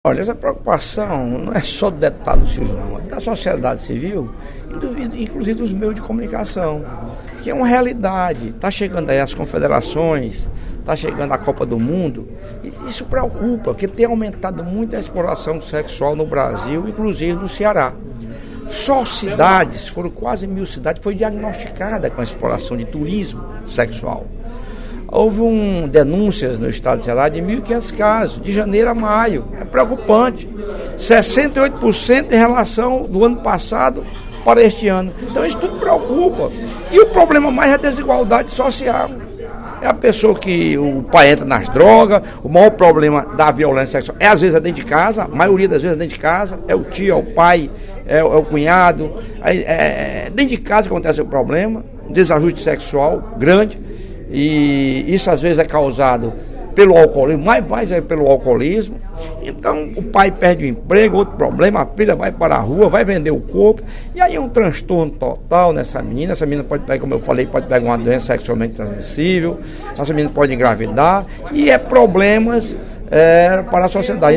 O deputado Lucílvio Girão (PMDB) destacou, na sessão plenária da Assembleia Legislativa desta quarta-feira (06/06), números referentes à exploração sexual no Ceará, classificados por ele como “preocupantes”, pelo fato de a prática estatisticamente ter aumentado em todo o País.